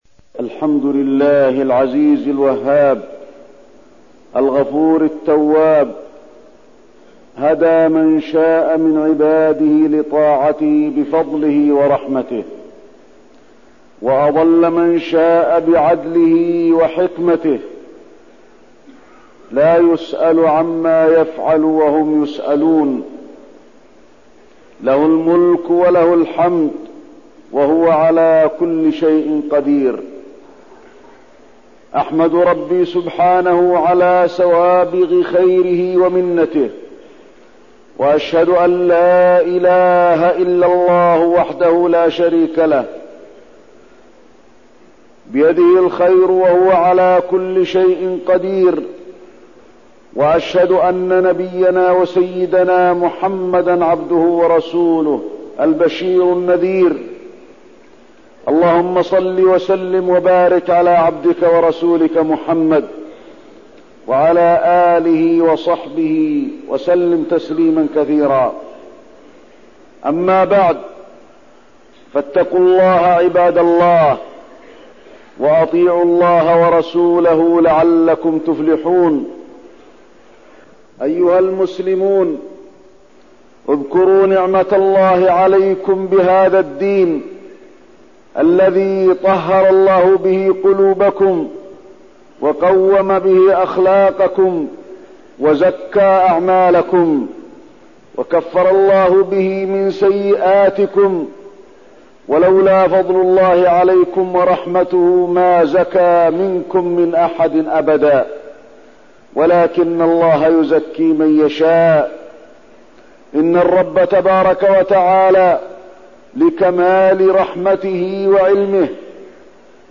تاريخ النشر ٢٦ رمضان ١٤١٣ هـ المكان: المسجد النبوي الشيخ: فضيلة الشيخ د. علي بن عبدالرحمن الحذيفي فضيلة الشيخ د. علي بن عبدالرحمن الحذيفي العبادات وأثرها واغتنام شهر رمضان